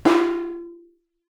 timpsnare_mf.wav